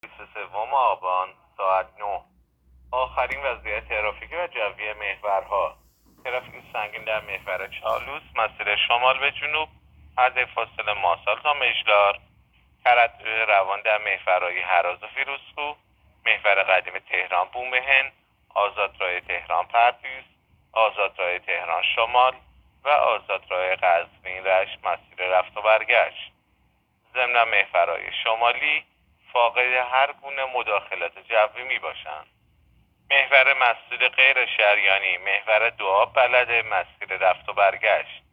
گزارش رادیو اینترنتی از آخرین وضعیت ترافیکی جاده‌ها ساعت ۹ بیست و سوم آبان؛